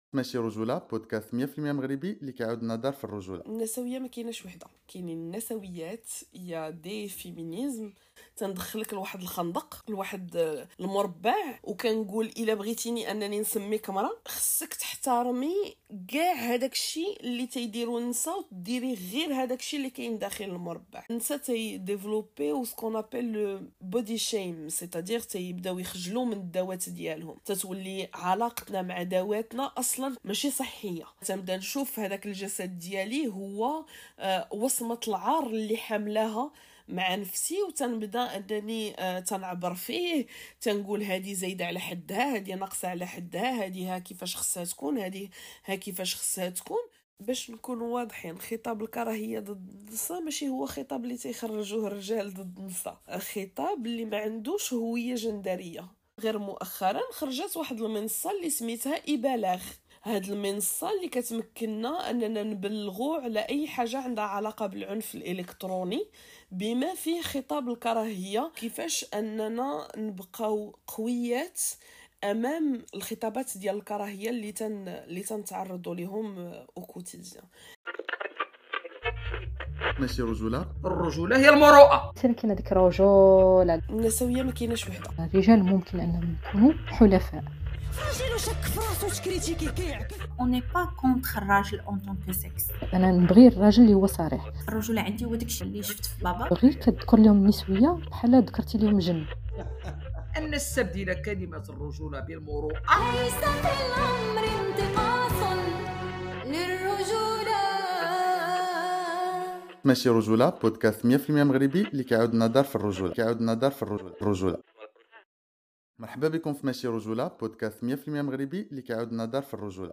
🗣 Ne ratez pas cette conversation inspirante qui vise à éveiller les consciences et à changer les mentalités !